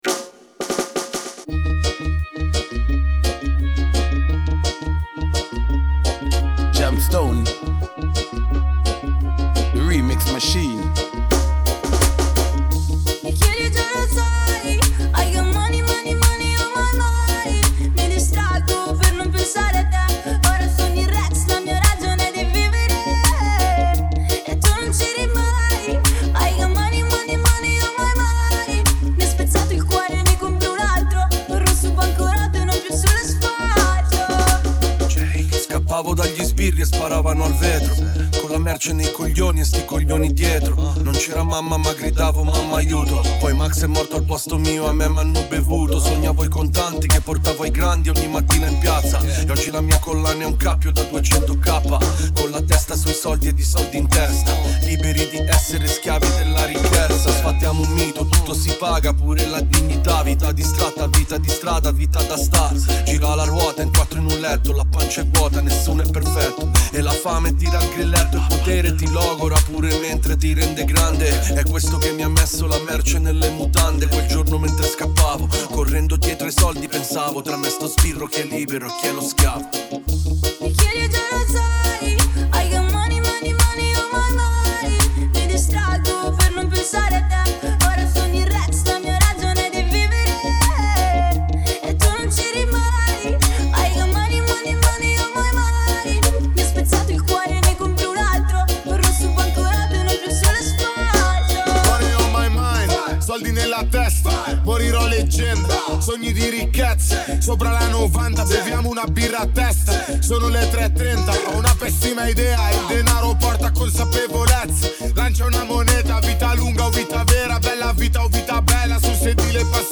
riddim